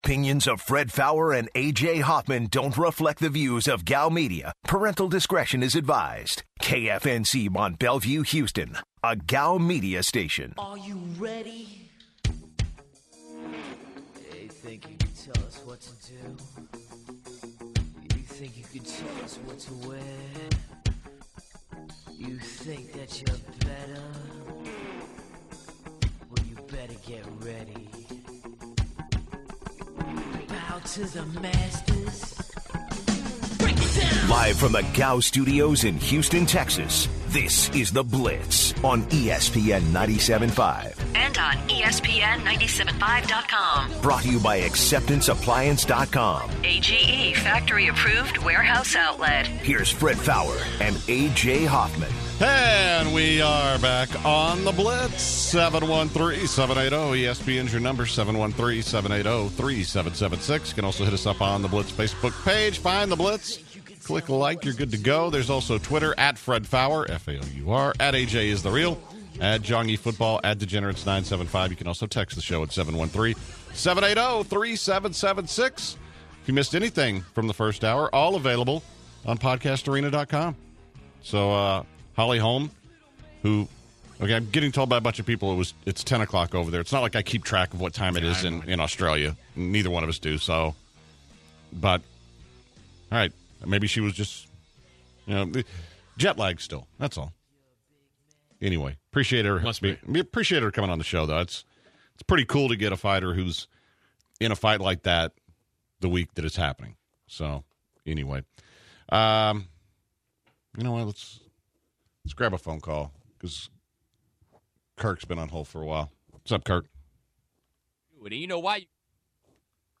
To open hour two, the guys take calls from fellow blitzers.